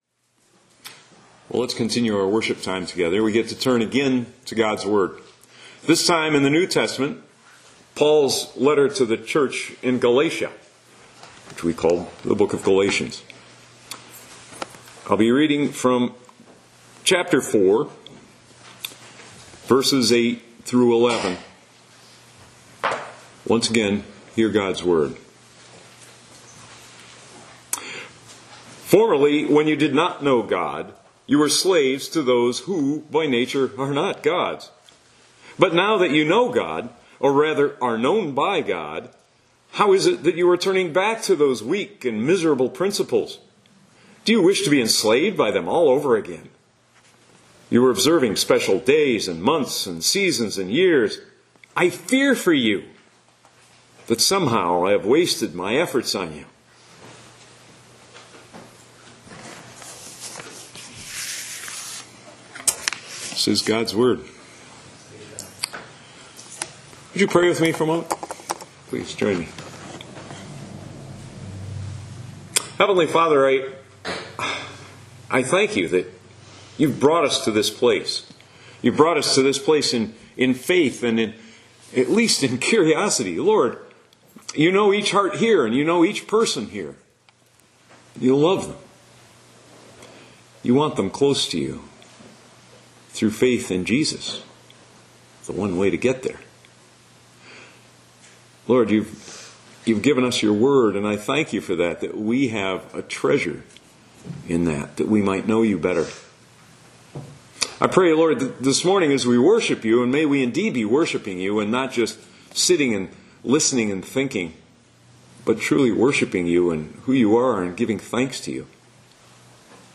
Audio Sermons - Holland Bible Church